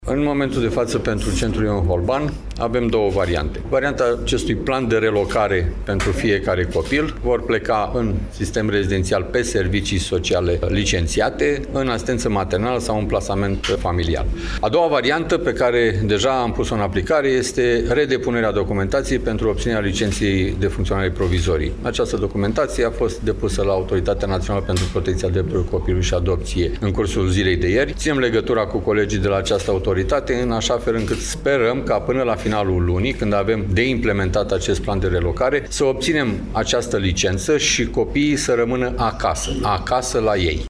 Astăzi, într-o conferință de presă, vicepreședinte al Consiliului Județean Iași, Marius Dangă, a arătat că planul de urgență și relocarea minorilor se face doar în cazul în care viața acestora este pusă în pericol.